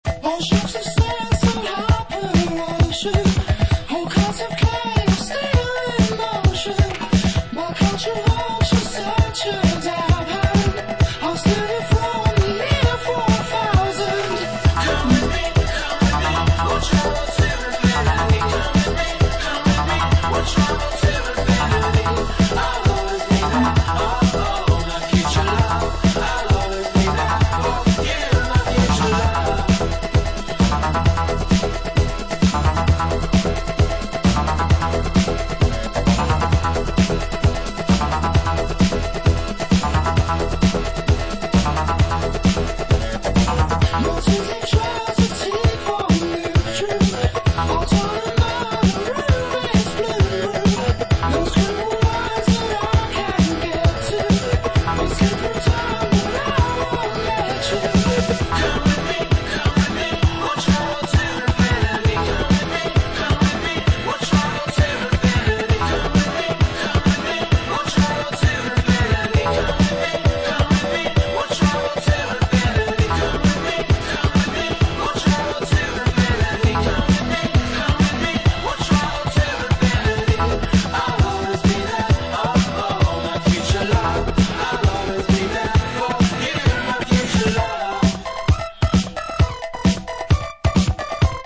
Uptempo rocking electro.
Electro Techno